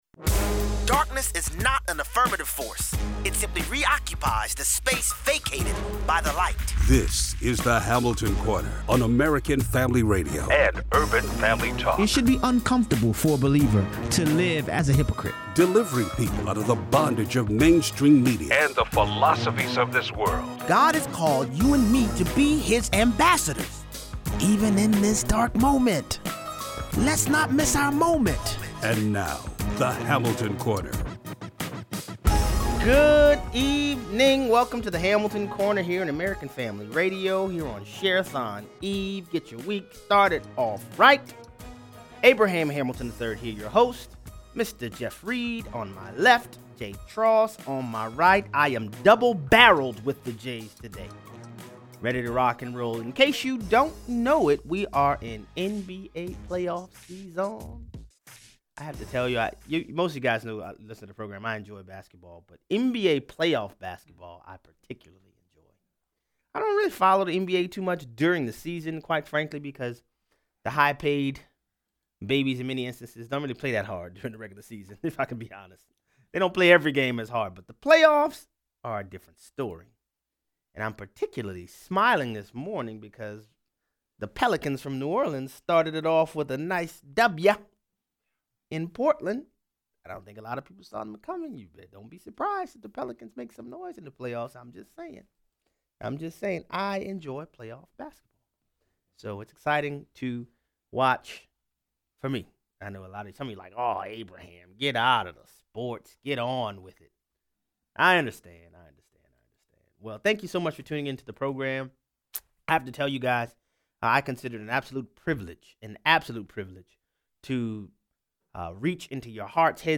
Callers weigh in